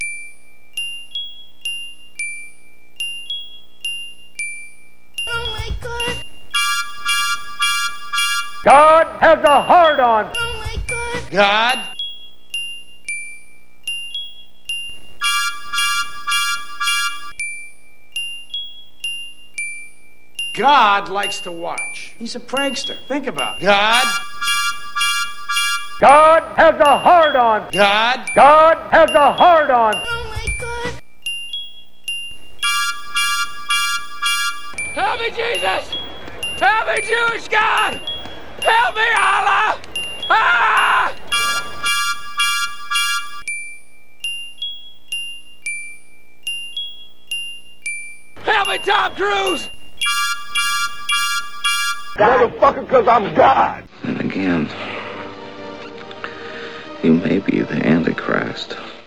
Tags: weird noise silly nonsense joke dumb outsider trippy trip-hop